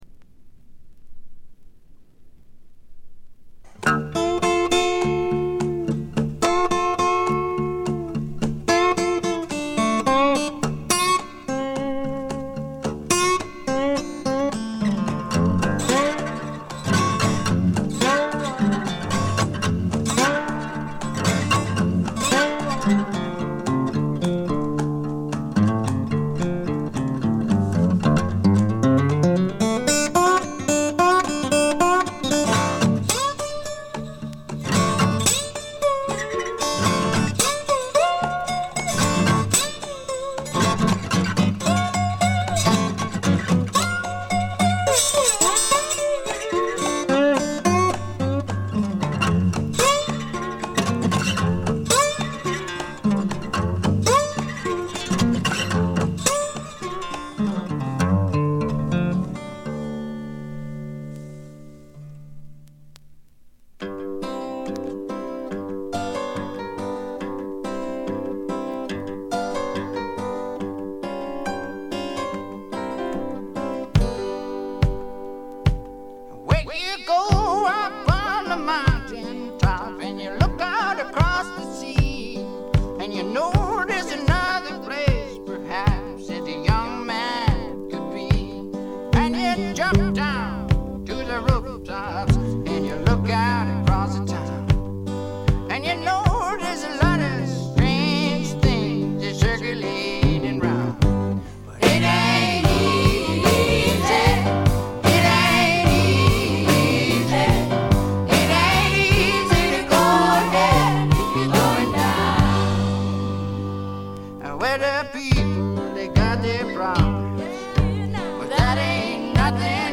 スワンプ路線とフォーキー路線が半々でどちらも素晴らしい出来ばえです。
試聴曲は現品からの取り込み音源です。
Vocals, Acoustic Guitar